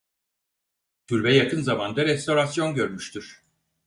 Pronounced as (IPA) /jɑˈkɯn/